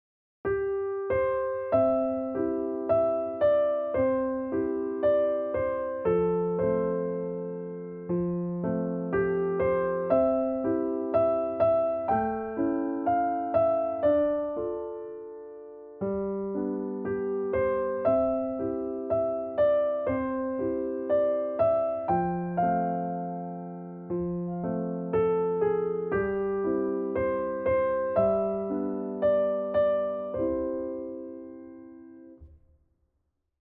Červená řeka na klavír - videolekce a noty pro začátečníky